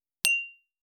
286食器をぶつける,ガラスをあてる,皿が当たる音,皿の音,台所音,皿を重ねる,カチャ,ガチャン,カタッ,
コップ効果音厨房/台所/レストラン/kitchen食器